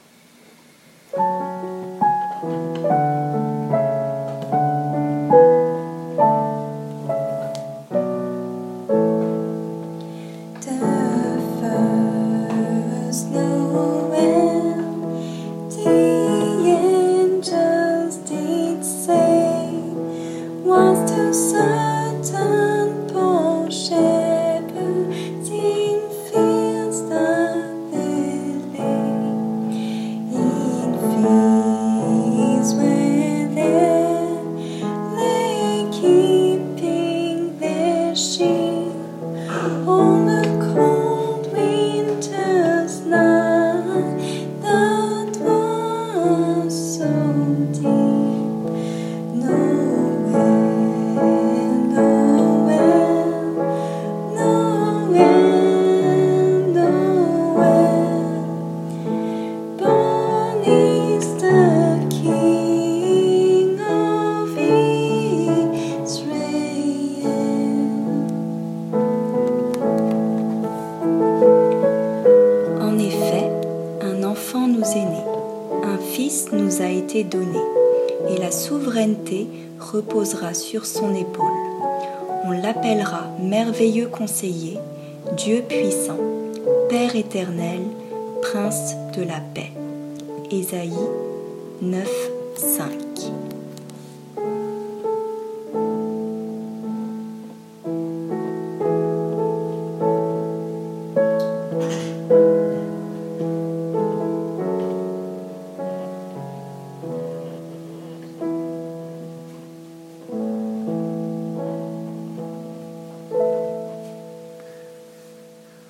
Vous connaissez sûrement ce chant “The first Noel”…